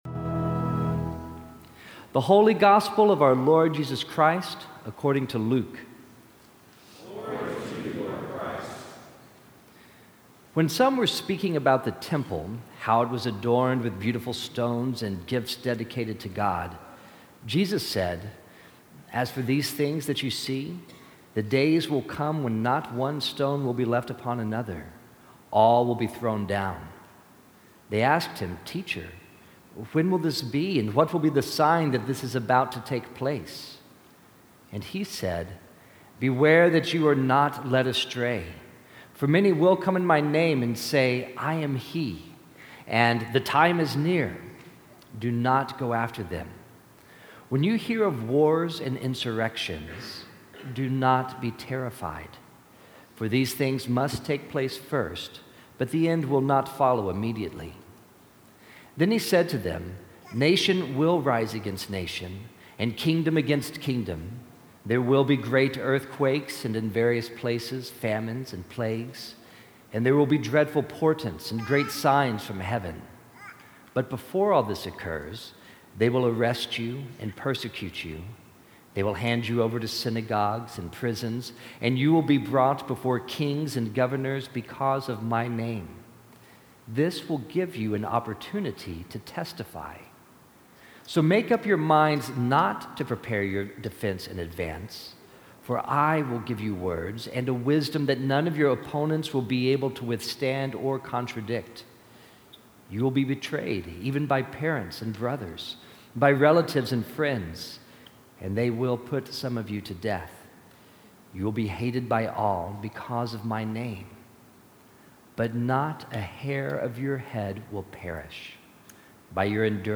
This sermon was preached at St. Bartholomew’s, Nashville, TN on Sunday, November 13, 2016. (NB: The audio is poor.) The previous week Donald J. Trump had been elected as President of the United States.